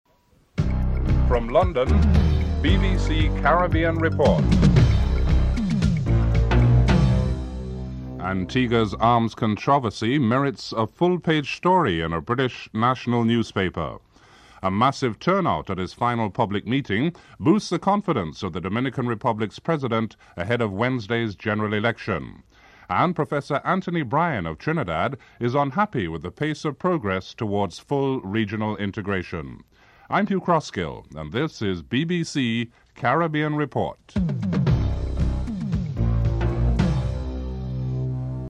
1. Headlines (00:00-00:40)
4. Financial News. (08:22-09:30)